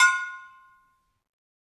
BrakeDrum1_Hammer_v1_Sum.wav